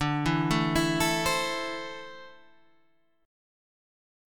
D7sus2 chord